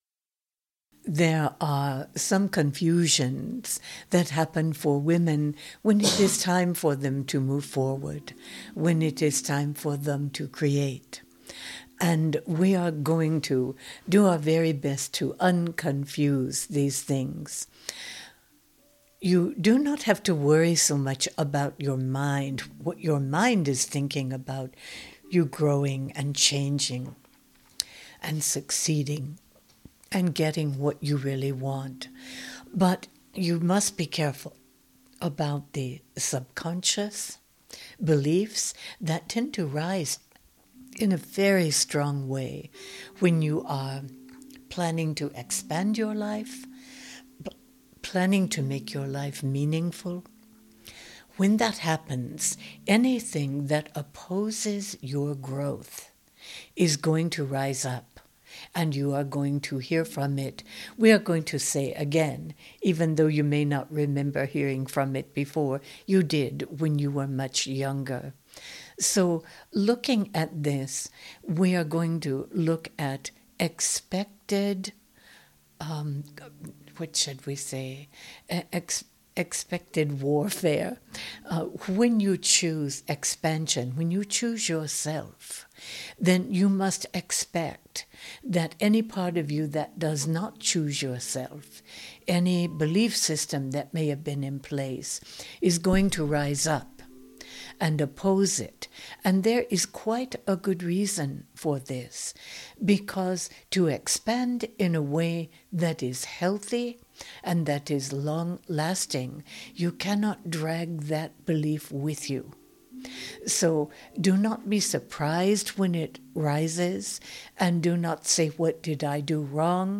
Genre: Meditation.